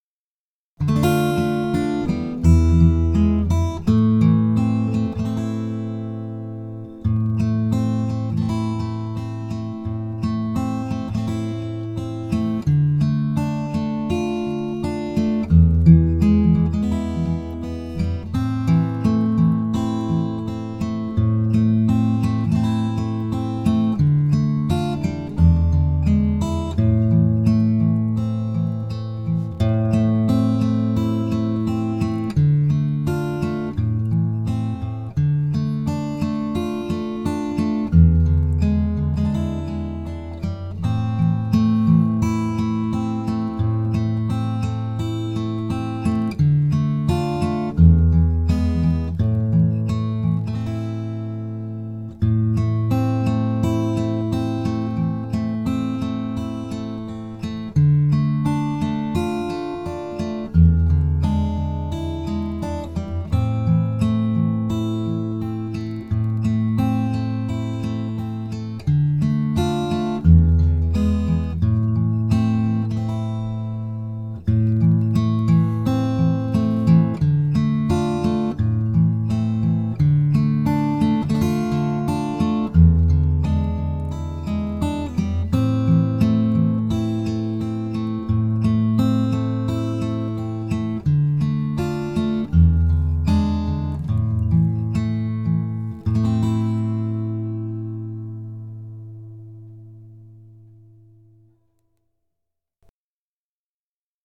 Am調
【カラオケ】
koujou_oke.mp3